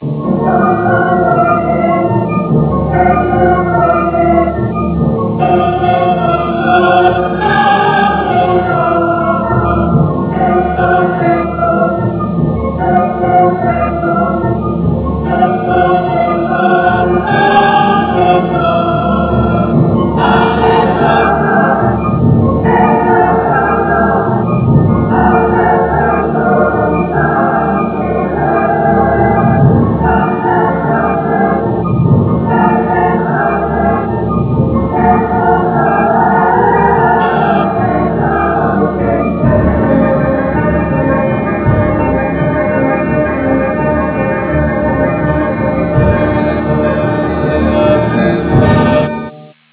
Colonna sonora
Original track music: